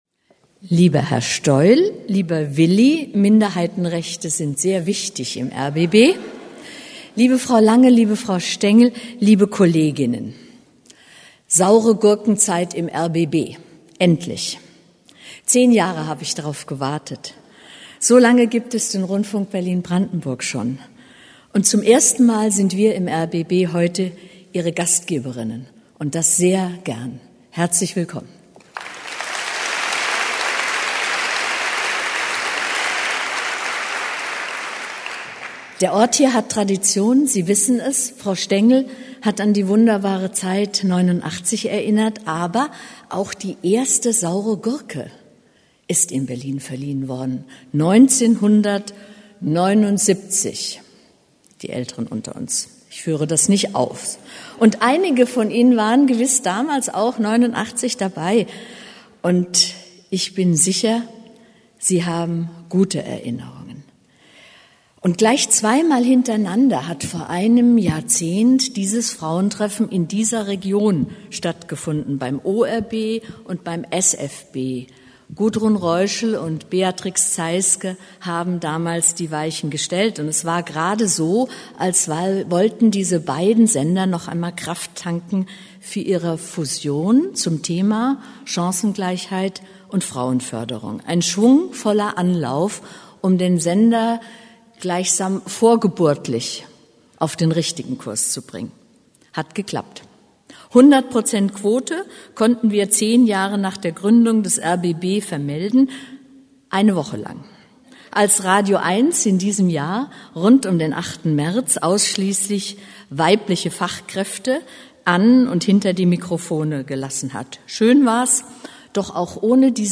Wer: Dagmar Reim, rbb-Intendantin
Was: Rede zum 36. Herbsttreffen der Medienfrauen
Wo: Berlin, rbb Berlin, Haus des Rundfunks, Großer Sendesaal